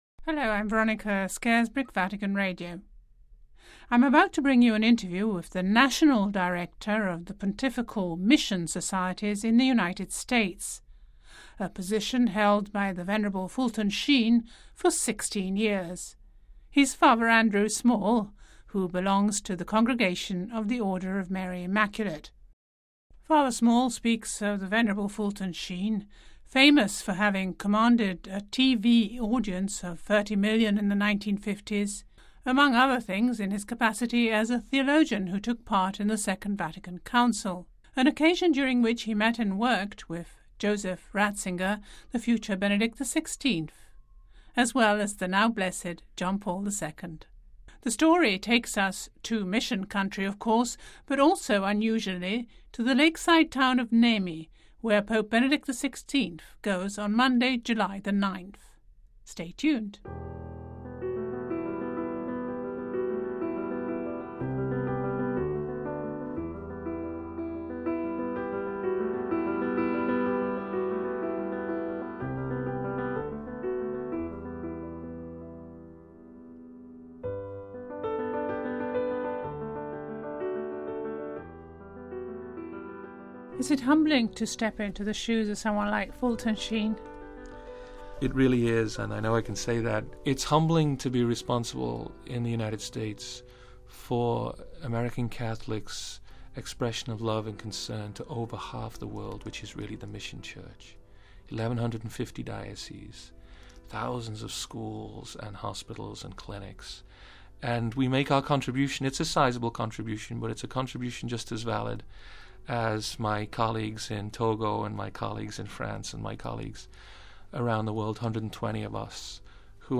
(Vatican Radio)